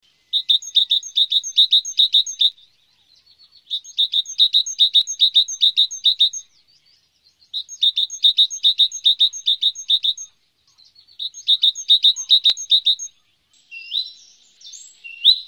Koolmees
koolmees.mp3